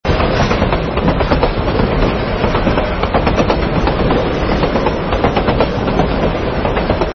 Treno in movimento
Rumore del treno dall'interno delle carrozze. Presente ambiente con cambi di timbro del rumore del vento come passando davanti a palazzi o stazione.
treno_marcia.mp3